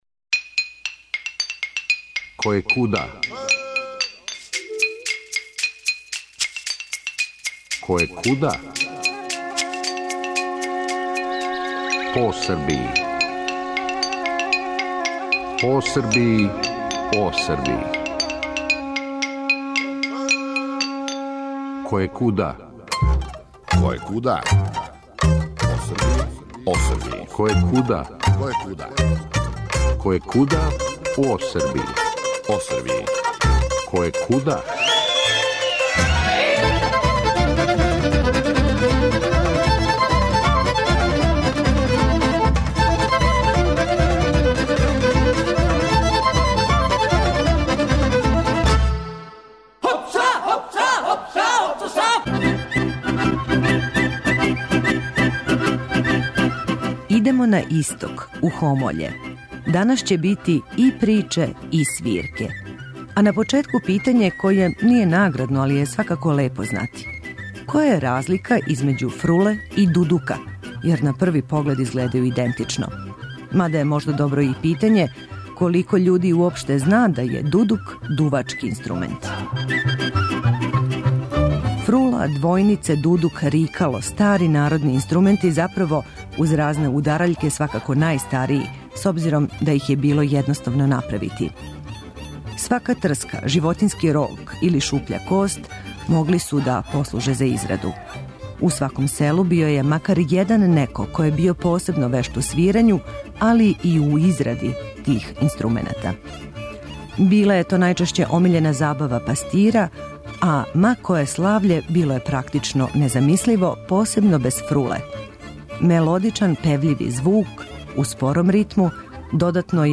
Идемо на исток, у Хомоље. Данас ће бити и приче, и свирке.